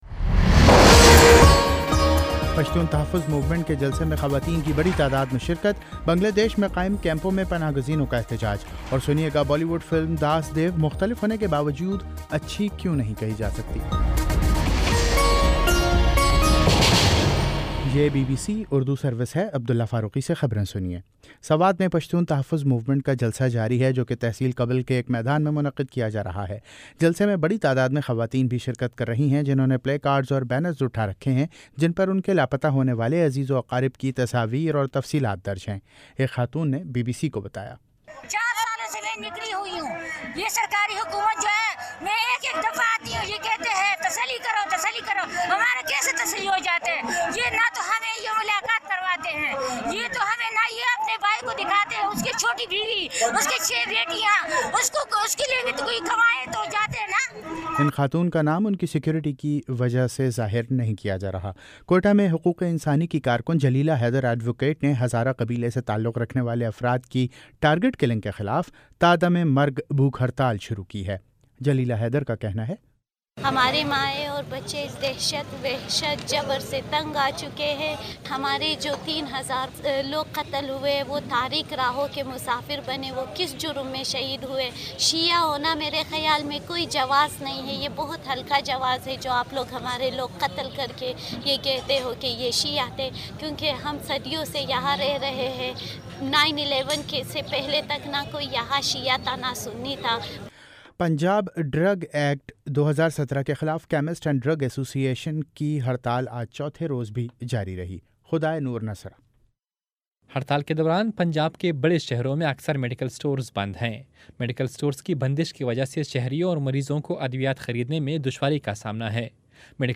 اپریل 29 : شام چھ بجے کا نیوز بُلیٹن